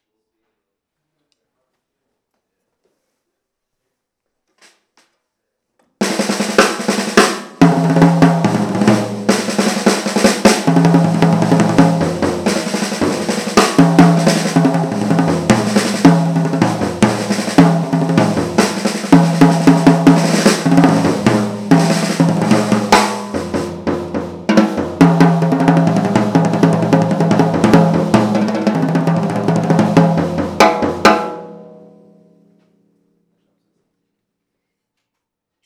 Their new Bop Kit has traditional sizes (8x12, 14x14, 14x18), and the ultra small Club Kit has a 7x10 tom, 12x13 floor tom and a 12x15 bass drum.
12/14/18 Canopus Bop kit with Zelkova snare.